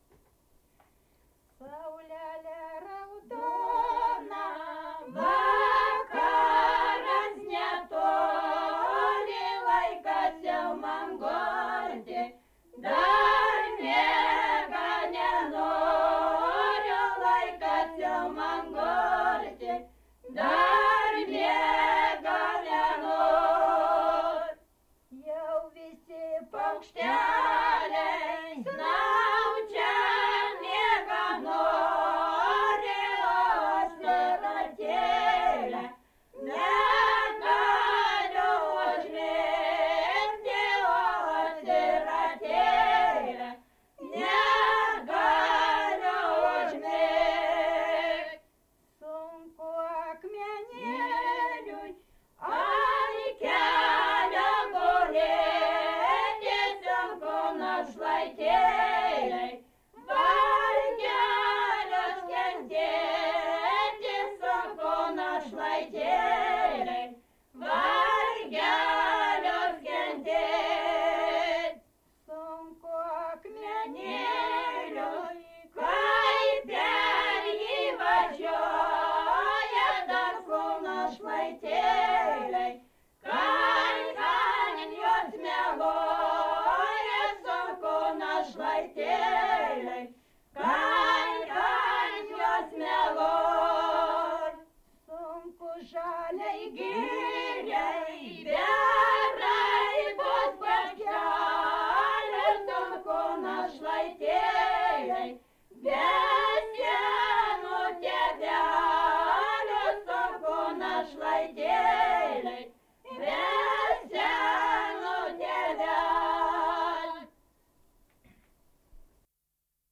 daina, vestuvių
Atlikimo pubūdis vokalinis
Pradžios nesigirdi beveik. Su kometaru.